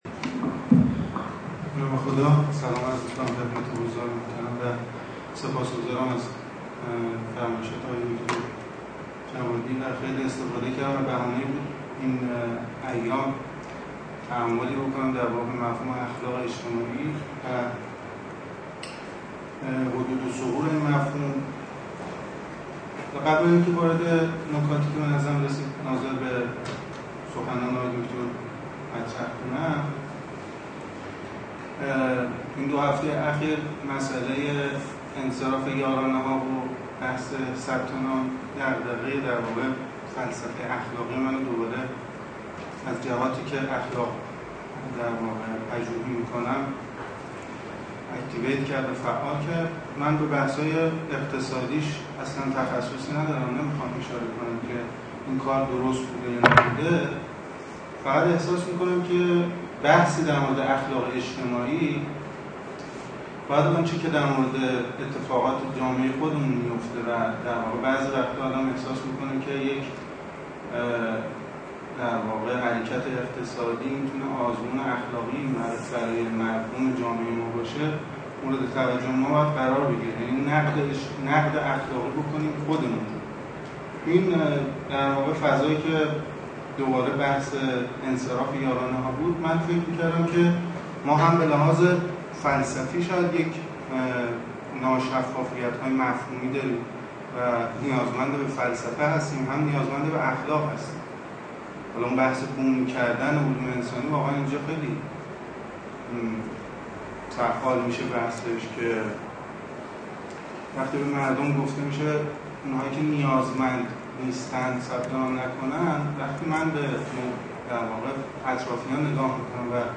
سخنرانی
خانه اندیشمندان علوم انسانی